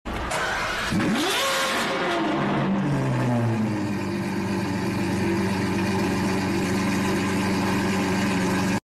Wildest exhaust ? 🏎 If sound effects free download